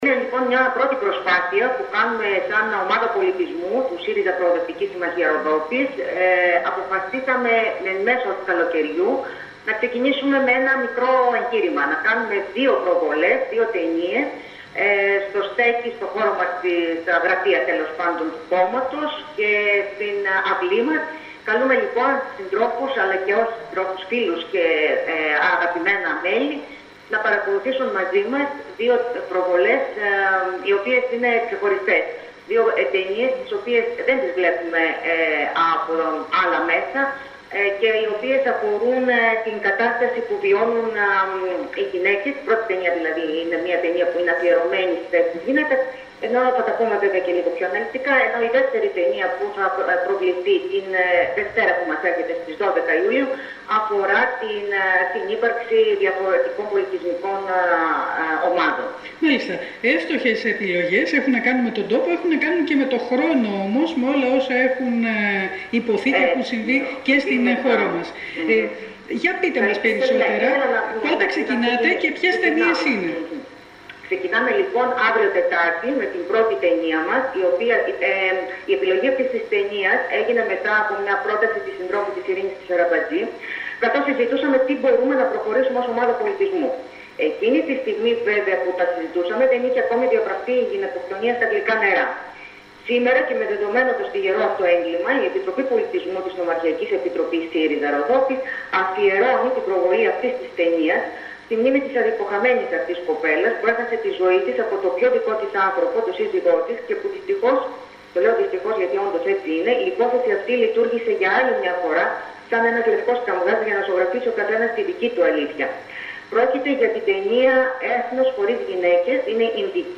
Συνέντευξη κείμενο-φωτογραφία